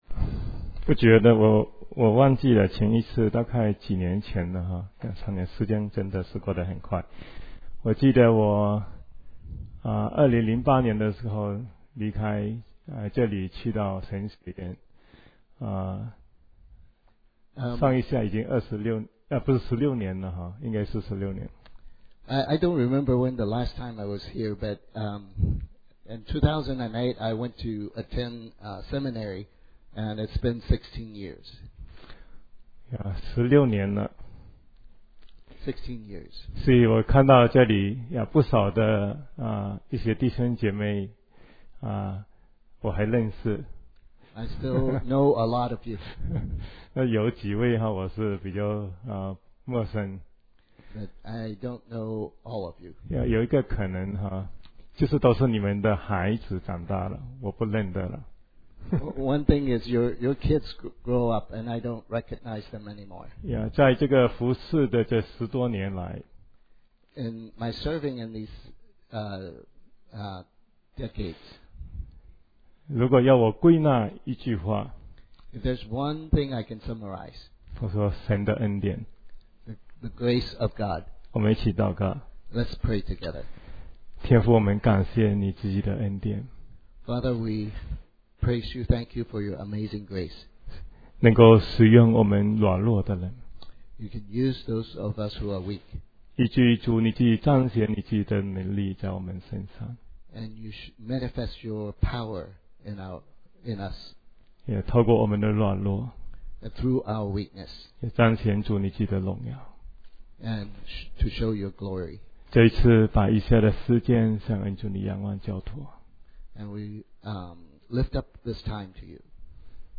Sermon Podcasts Downloads | Greater Kansas City Chinese Christian Church (GKCCCC)